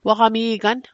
Pronunciation Guide: wa·hga·mii·gan